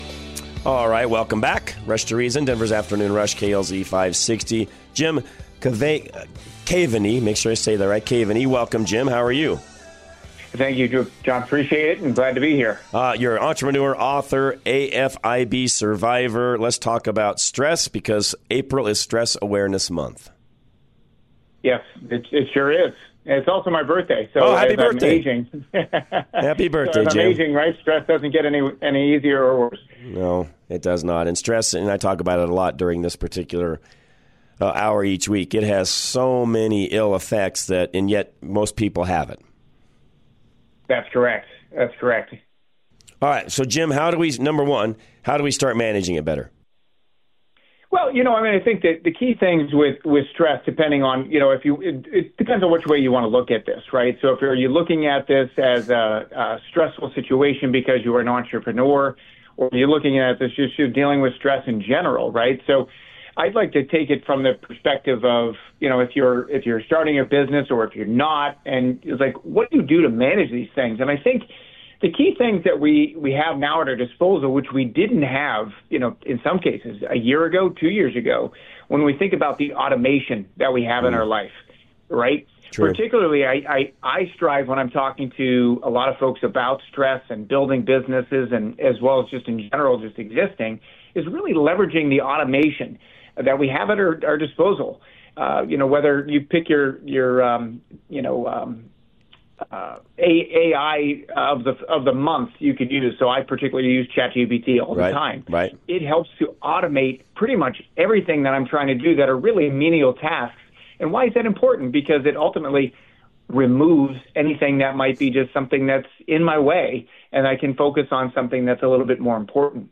Rush To Reason - Interviews Stress, AFib & the Lies We’ve Been Told Apr 09 2025 | 00:16:38 1x 00:00 / 00:16:38 Subscribe Share Spotify Pocket Casts RSS Feed Share Link Embed